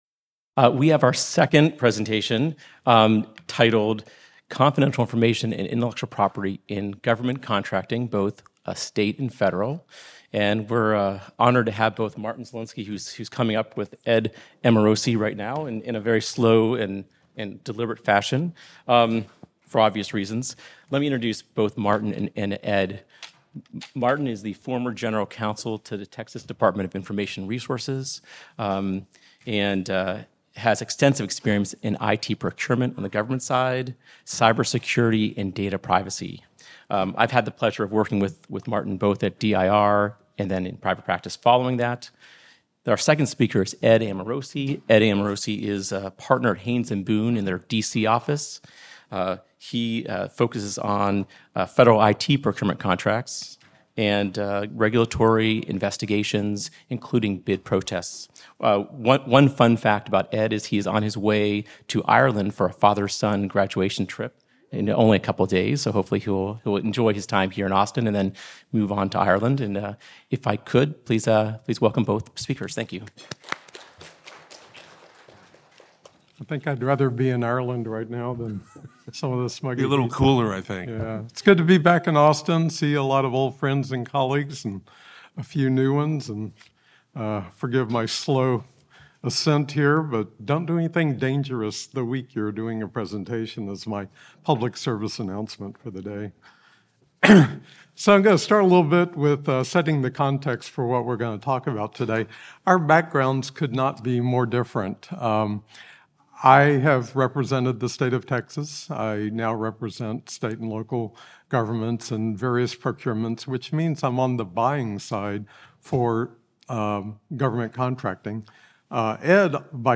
a discussion of recent disputes over IP rights in the public sector. Originally presented: May 2024 Technology Law Conference